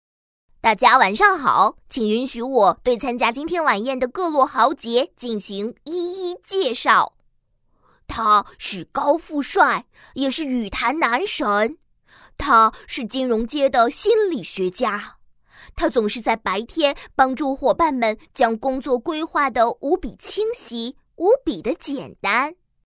女国90_动画_童声_平安明星年会视频男童.mp3